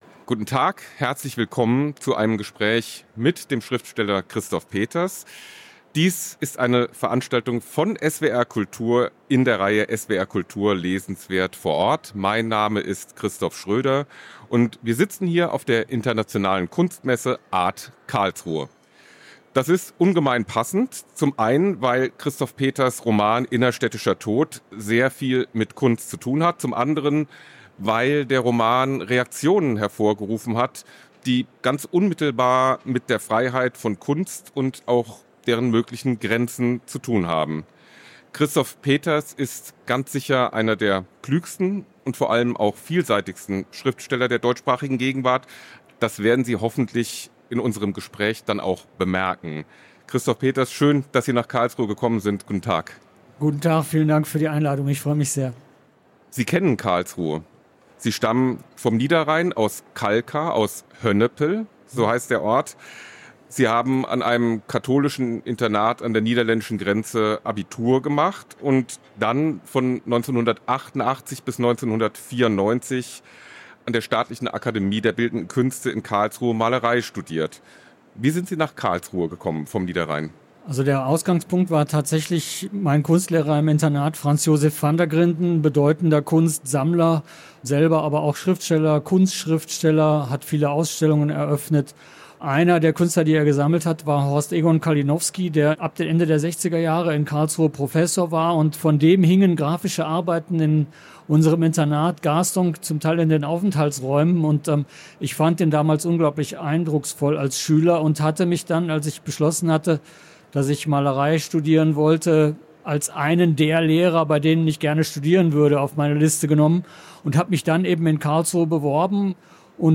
Was darf Kunst? – Christoph Peters im Gespräch über seinen Roman "Innerstädtischer Tod"
Mit "Innerstädtischer Tod" beschließt Christoph Peters seine Romantrilogie, die elegant um Kunst, Politik und die aufgewühlte Gegenwart kreist. Ein Gespräch über die Möglichkeiten und Grenzen von Kunst in einer zersplitterten Welt.